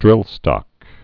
(drĭlstŏk)